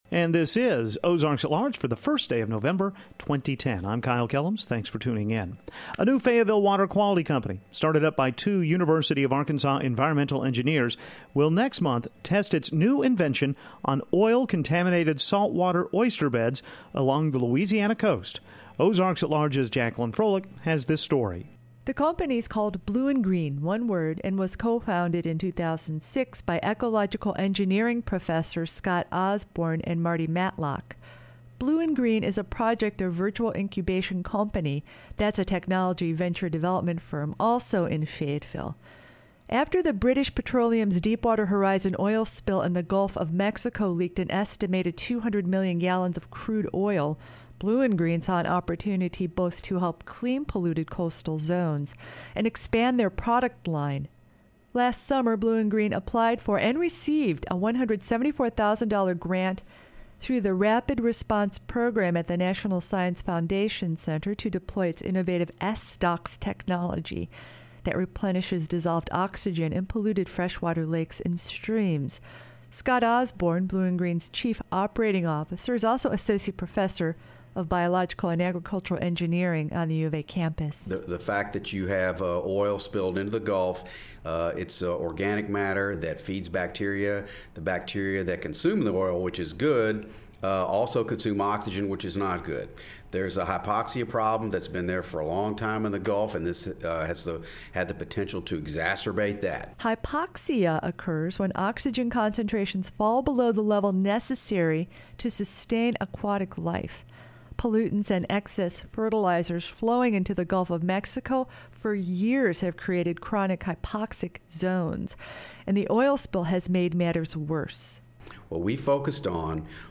On this edition of Ozarks at Large, a conversation with John Logan Burrow, Washington County Election Commissioner, about what happens before, during and after tomorrow's election.